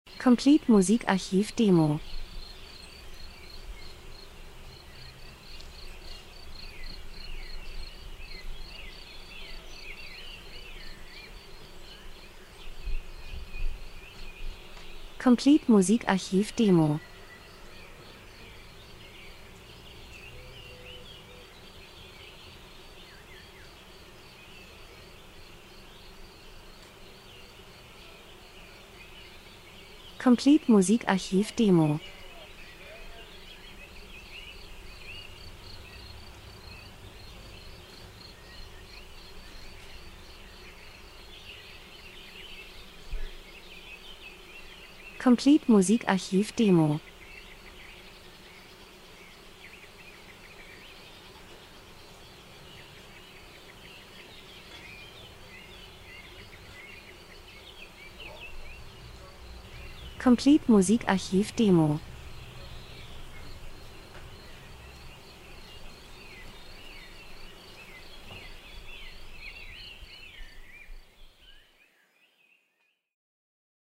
Sommer -Geräusche Soundeffekt Natur Wiese Bienen 01:13